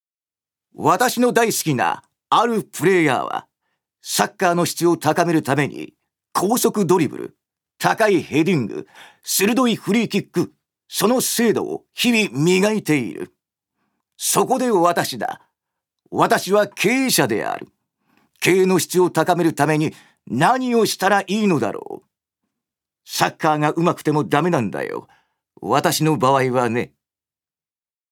預かり：男性
セリフ２